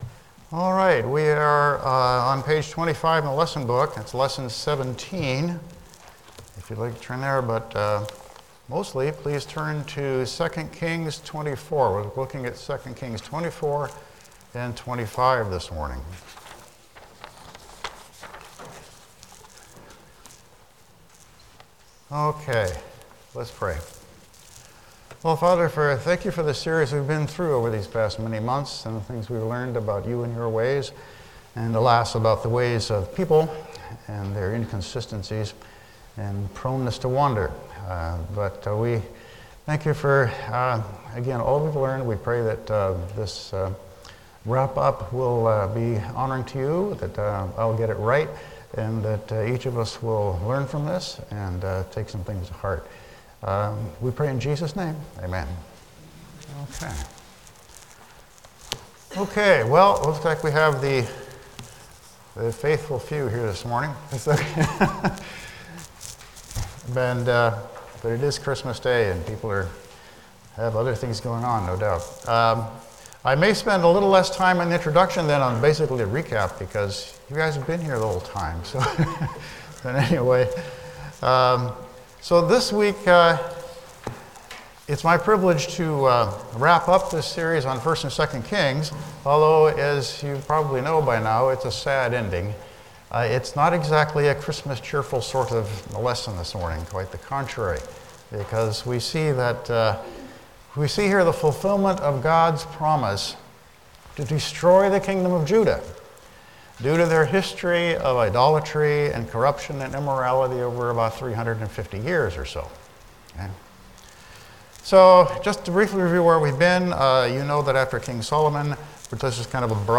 A Study of First and Second Kings Service Type: Sunday School « Lesson 16